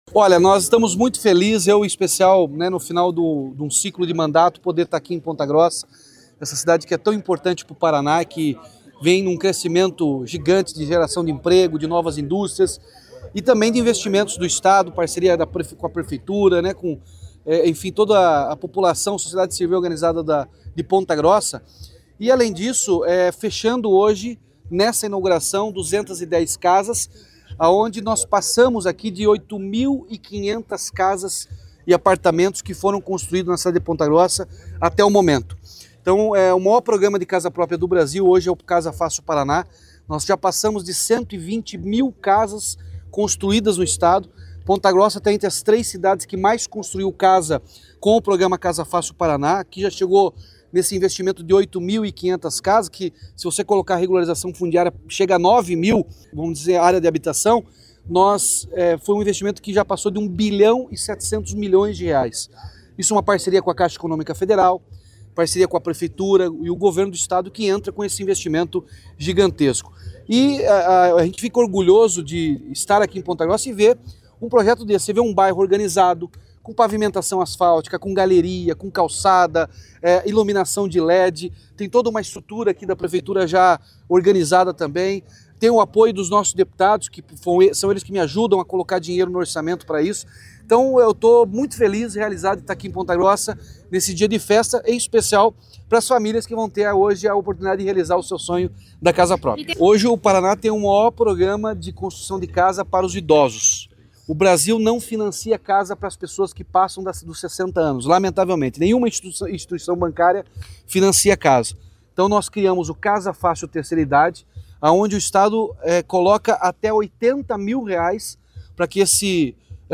Sonora do governador Ratinho Junior sobre a entrega de 220 casas em Ponta Grossa